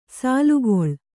♪ sālugol